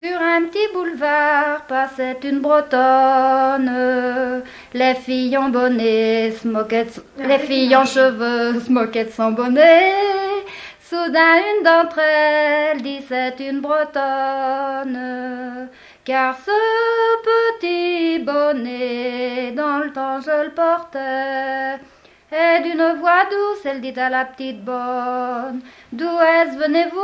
Genre strophique
Témoignages sur le mariage et chansons traditionnelles
Pièce musicale inédite